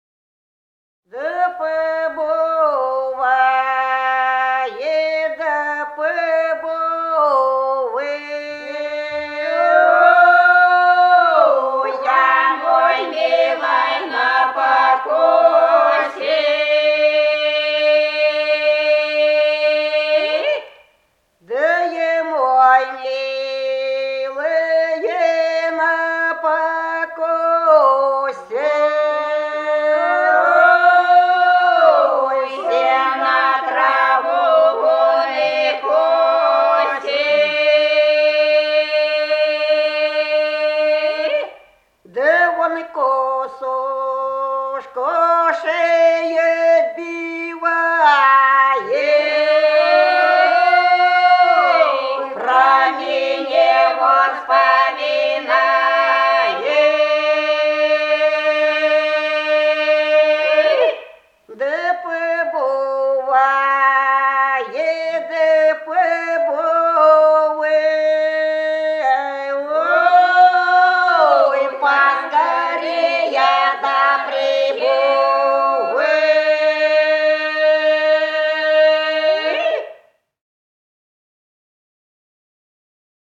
Голоса уходящего века (село Фощеватово) Побувай же, побувай мой милый на покосе (покосная)